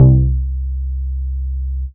Fuller Note.wav